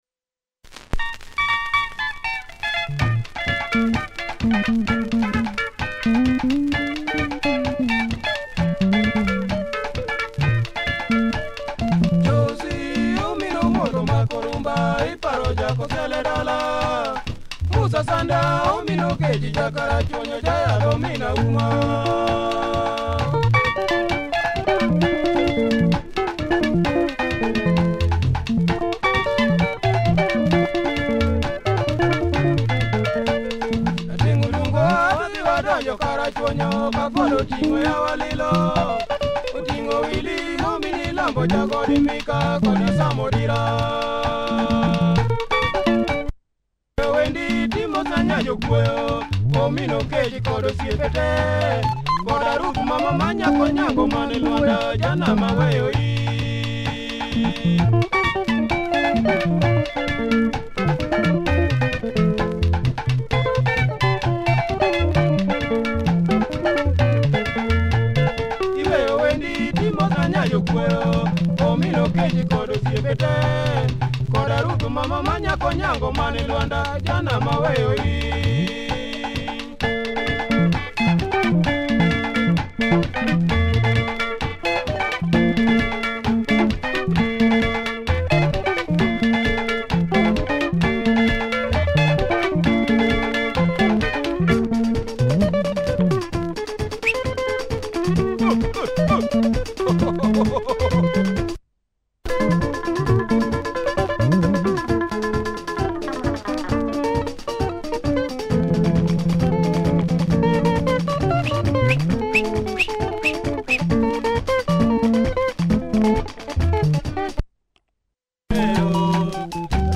Bumpin’ Luo Benga